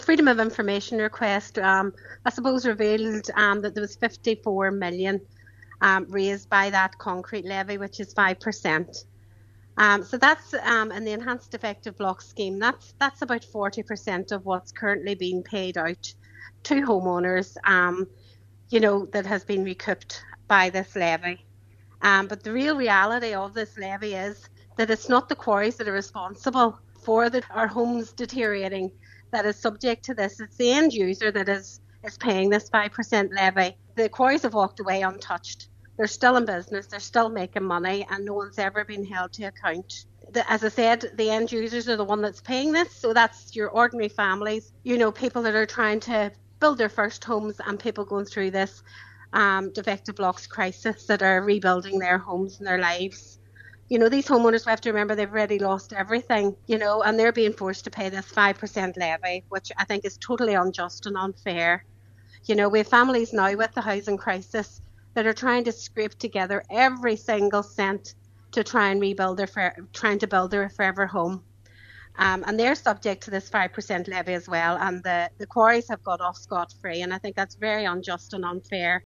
Cllr Beard says it is not fair the cost falls on ordinary people and families while the quarries remain unaffected: